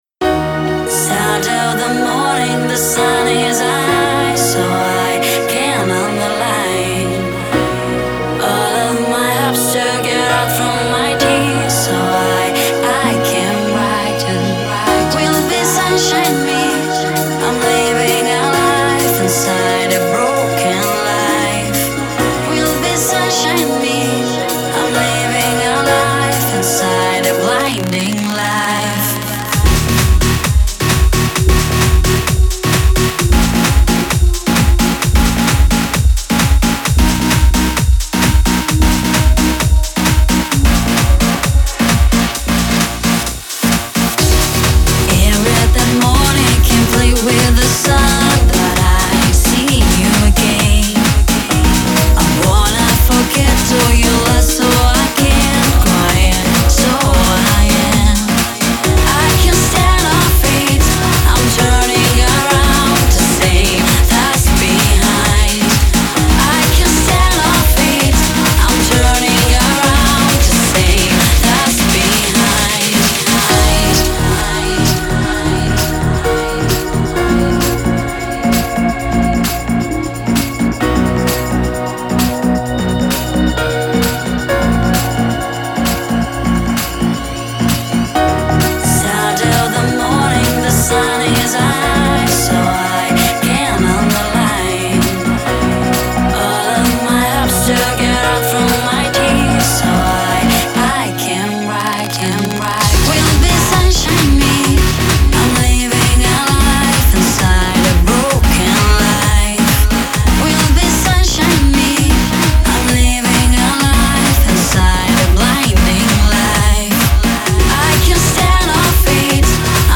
Категория: Club Music - Клубная музыка